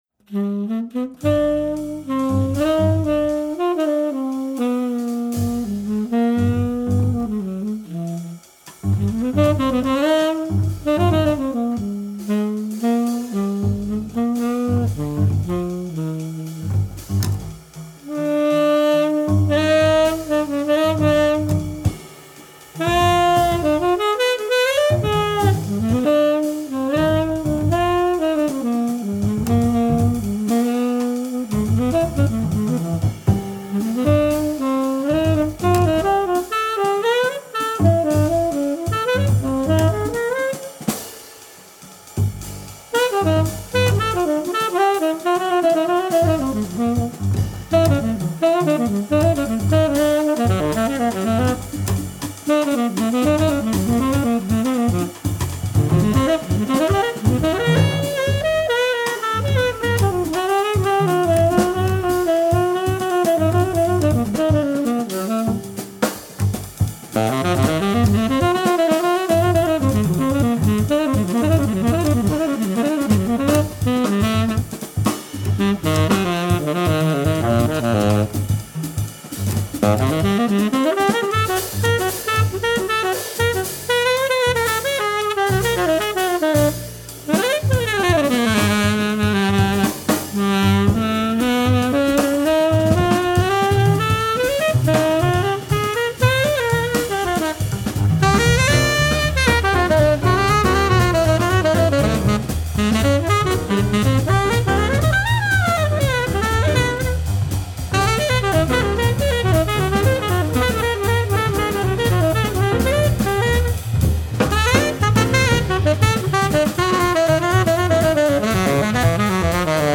Tenor Saxophone
Alto Saxophone
Piano
Bass
Drums